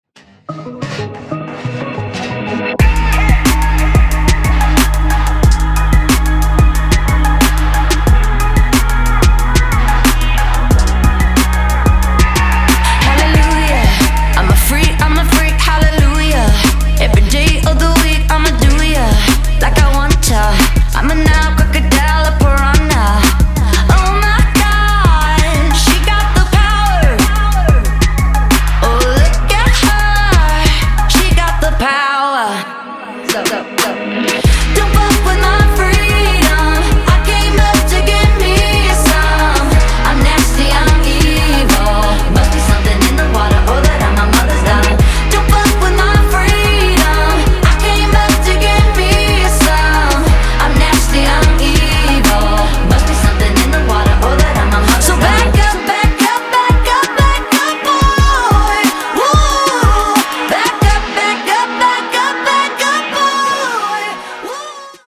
• Качество: 224, Stereo
громкие
dance
Electronic
Trap
красивый женский голос
воодушевляющие